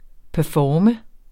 Udtale [ pəˈfɒːmə ]